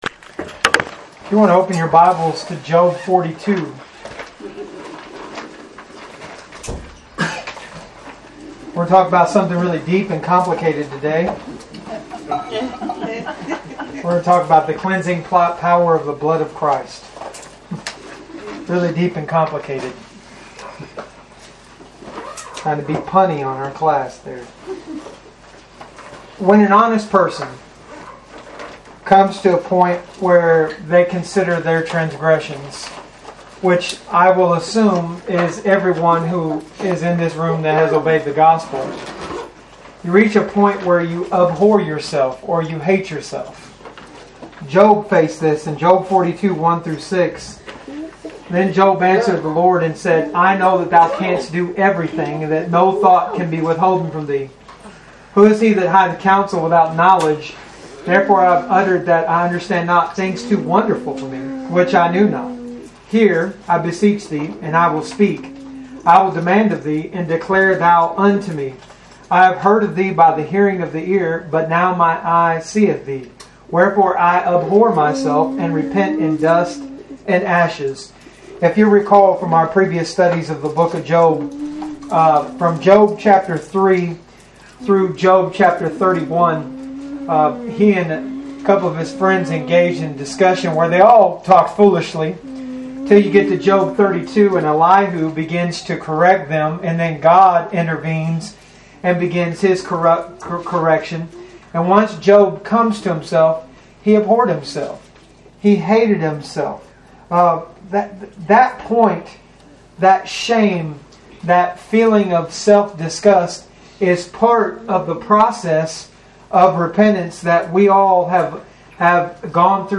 Free Bible Study Materials and Audio Sermons